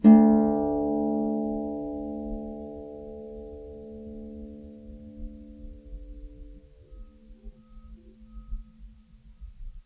KSHarp_D2_mf.wav